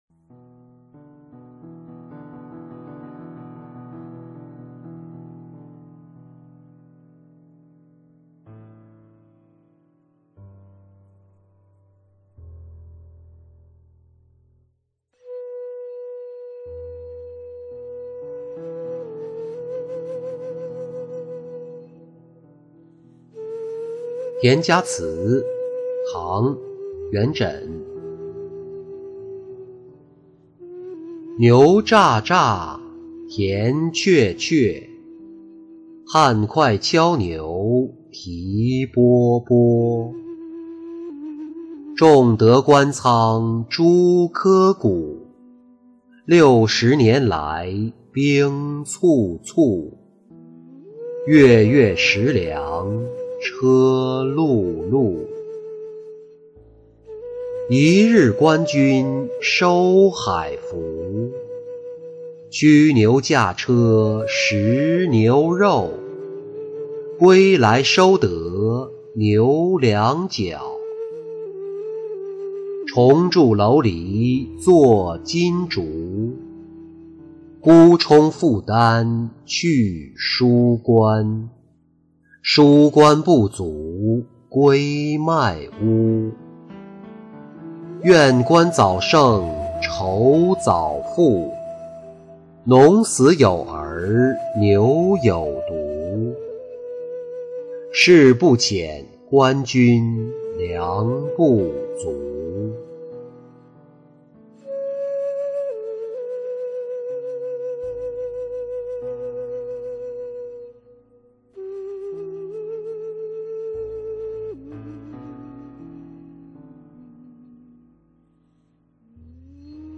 田家词-音频朗读